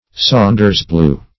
Meaning of saunders-blue. saunders-blue synonyms, pronunciation, spelling and more from Free Dictionary.
Search Result for " saunders-blue" : The Collaborative International Dictionary of English v.0.48: Saunders-blue \Saun"ders-blue`\, n. [Corrupted fr. F. cendres bleues blue ashes.]